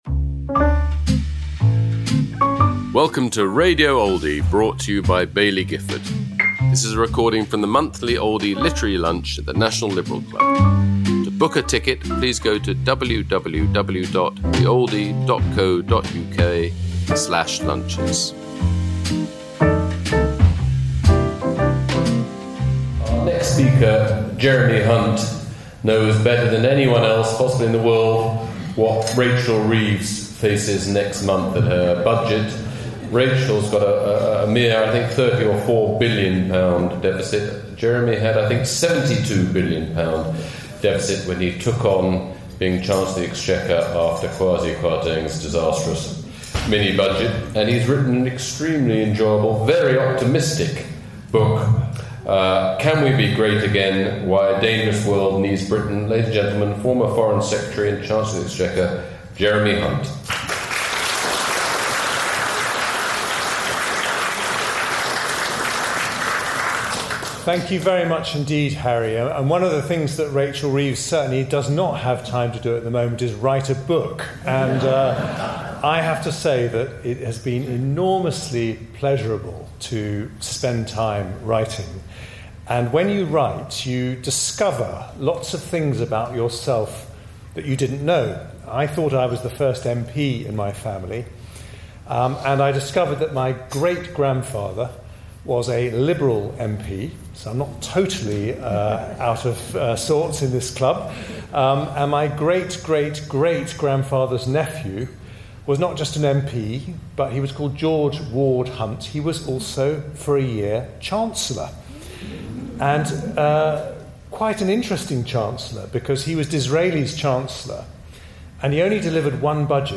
Jeremy Hunt speaking about his new book, Can We Be Great Again?: Why a Dangerous World Needs Britain, at the Oldie Literary Lunch, held at London’s National Liberal Club, on October 7th 2025.